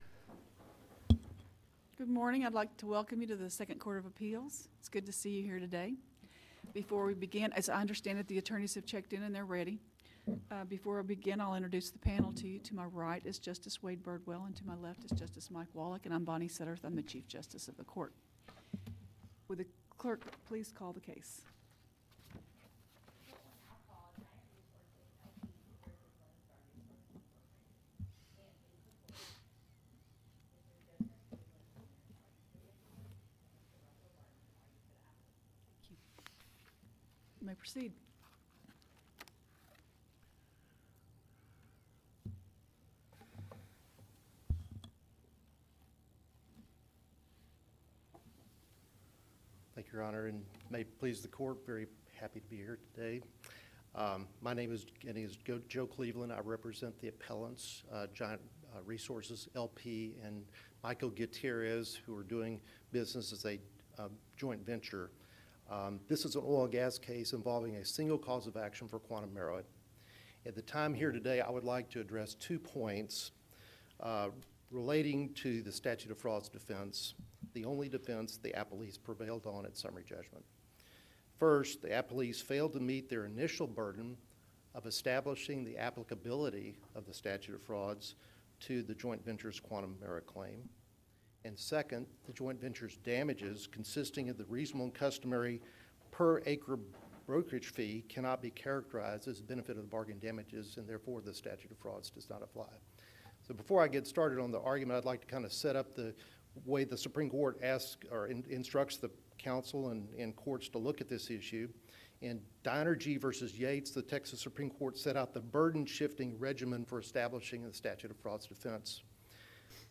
TJB | 2nd COA | Practice Before the Court | Oral Arguments | 2022